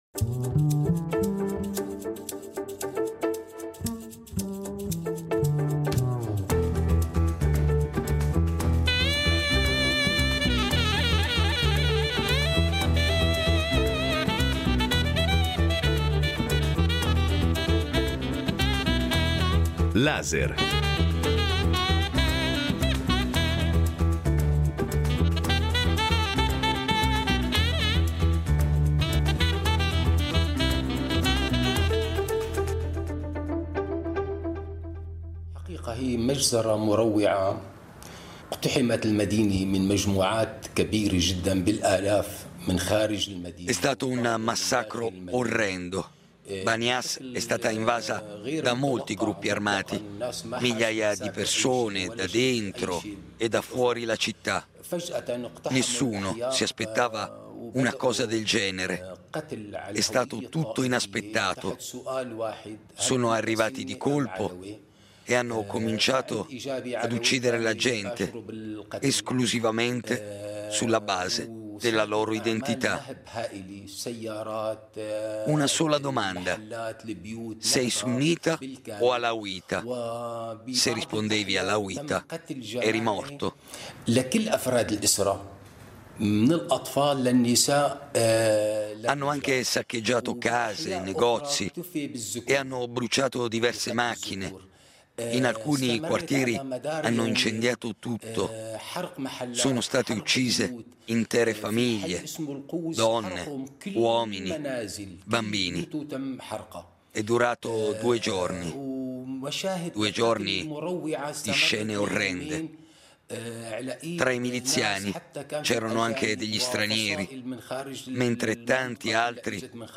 Questo audio documentario, prodotto in Siria nelle scorse settimane, parte proprio da lì.